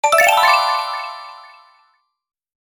Immerse yourself in the vibrant tapestry of celebrations with our Festivities Sound Effects.
Christmas-game-menu-ui-success.mp3